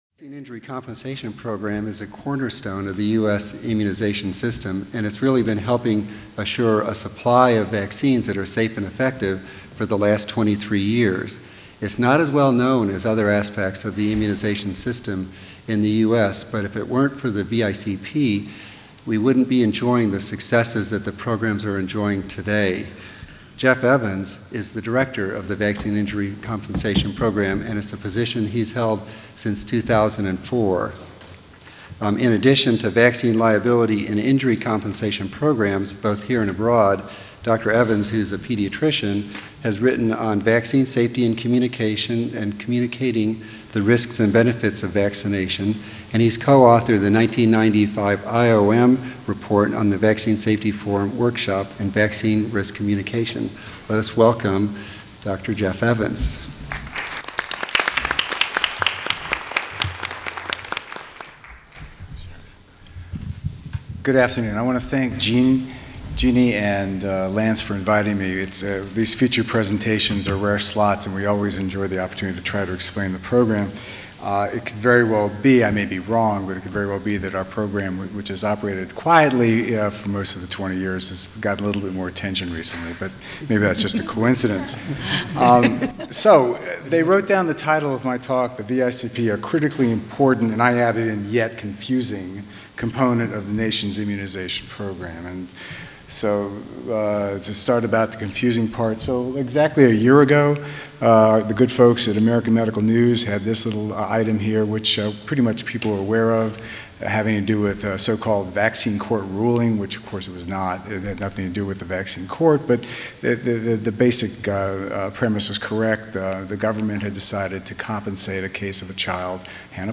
Abstract: The Vaccine Injury Compensation Program: A Critically Important Component of the Nation's Immunization Program (43rd National Immunization Conference (NIC))
Recorded presentation